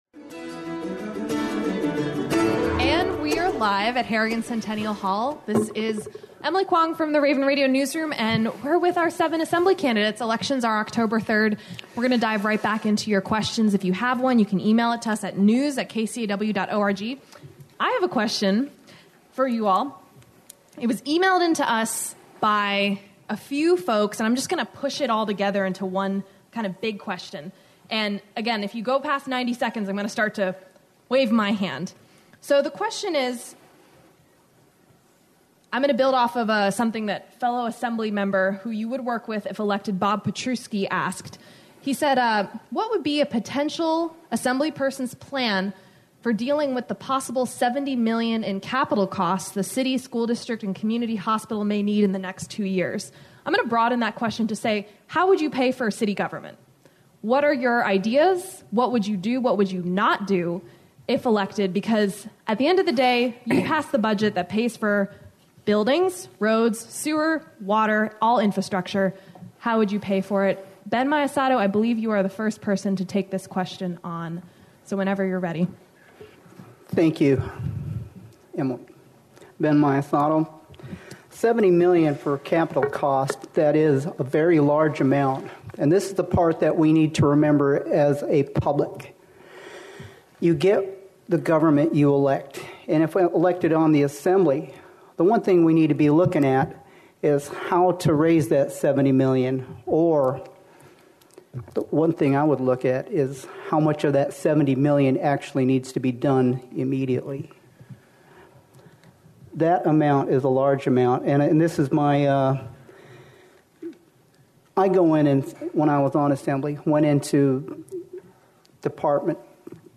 They represent a diverse cross section of political views and visions and gathered for Raven Radio’s live forum on Tuesday night (09-22-17) to take questions from voters.